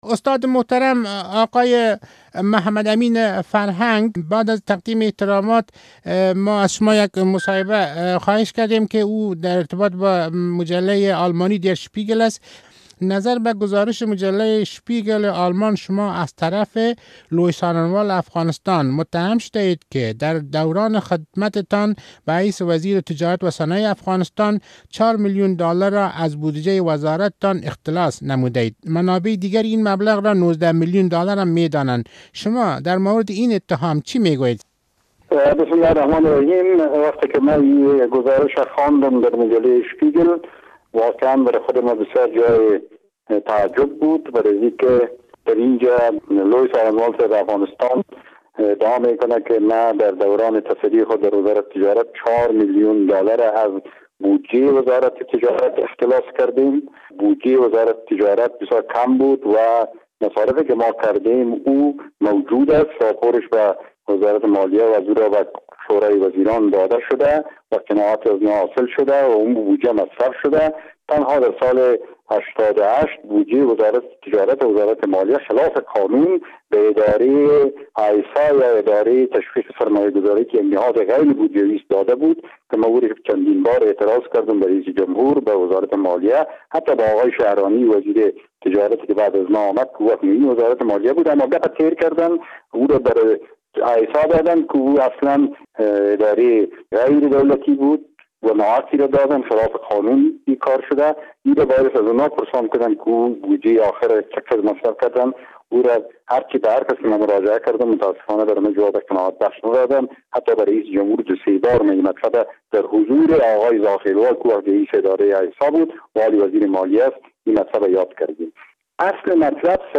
مصاحبه با محمد امین فرهنگ سابق وزیر تجارت افغانستان